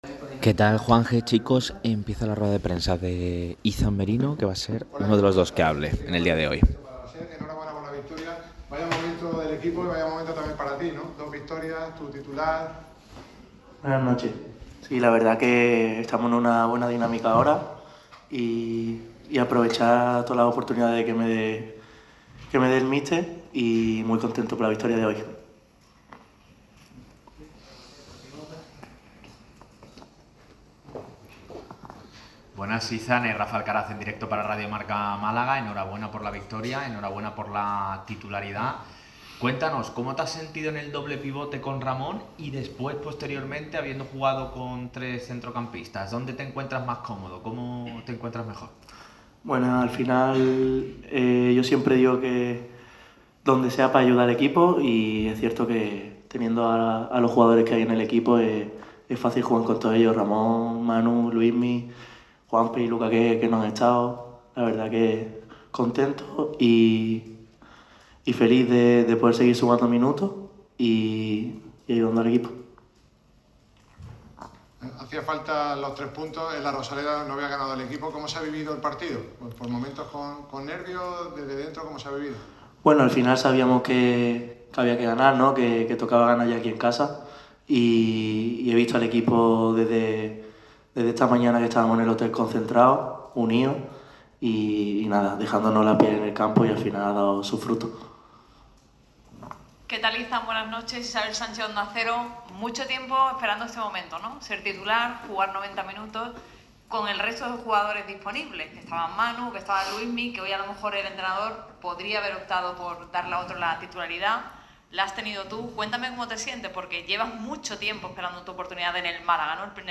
ha comparecido ante los medios en sala de prensa después de la victoria por la mínima ante el Tenerife.